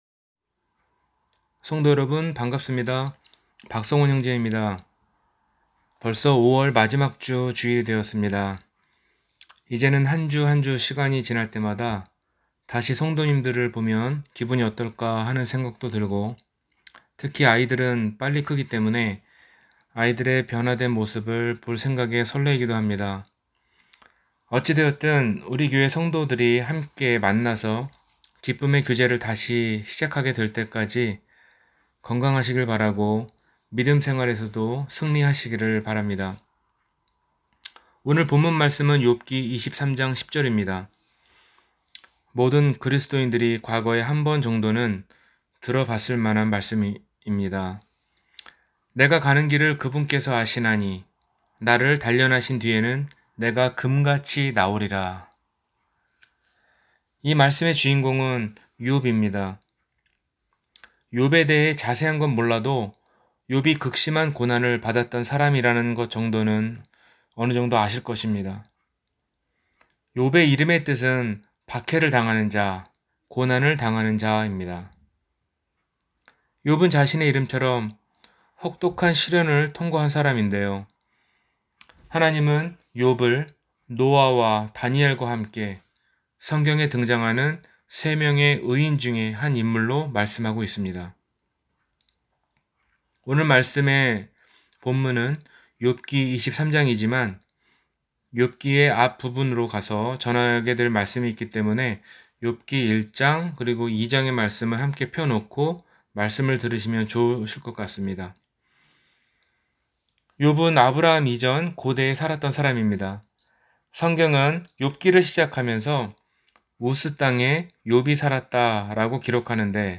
의인이 당하는 고난 – 주일설교